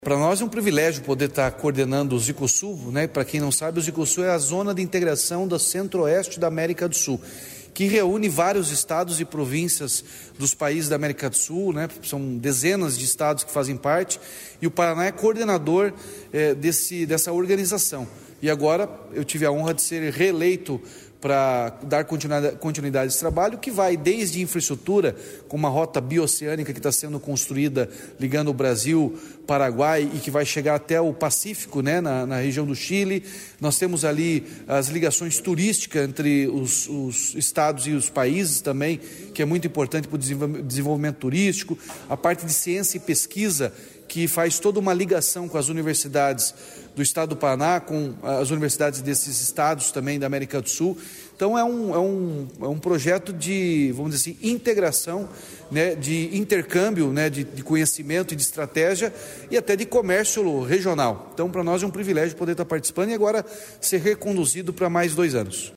Sonora do governador Ratinho Junior sobre a recondução do Estado na presidência da Zicosul